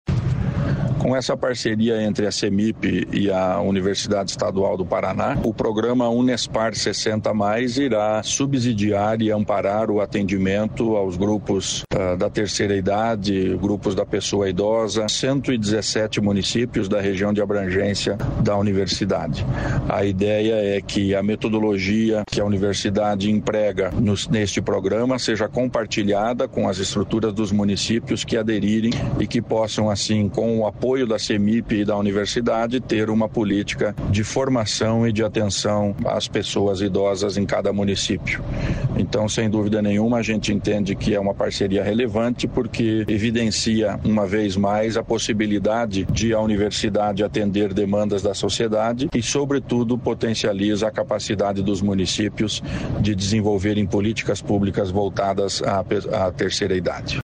Sonora do secretário da Ciência, Tecnologia e Ensino Superior do Paraná, Aldo Nelson Bona, sobre pesquisa e extensão voltadas a pessoas idosas